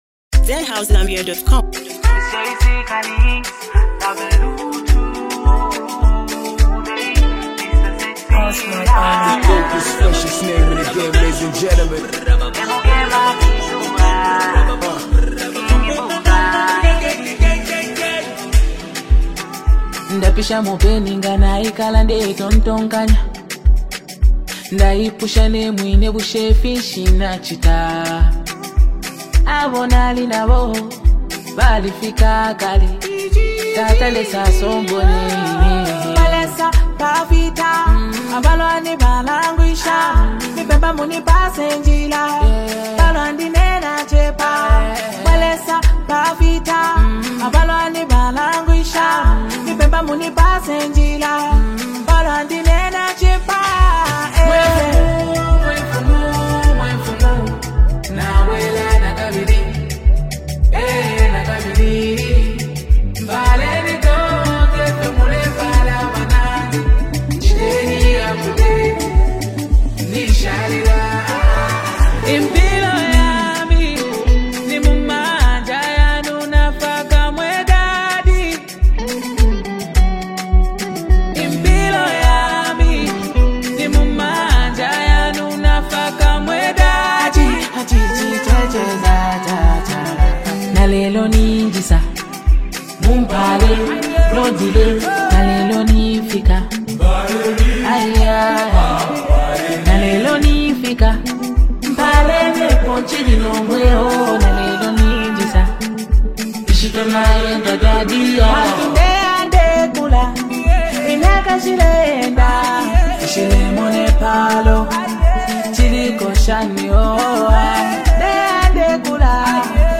hard-hitting bars, epic vibes, and pure Zambian energy!